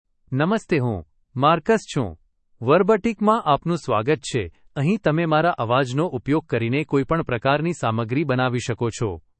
MaleGujarati (India)
MarcusMale Gujarati AI voice
Marcus is a male AI voice for Gujarati (India).
Voice sample
Marcus delivers clear pronunciation with authentic India Gujarati intonation, making your content sound professionally produced.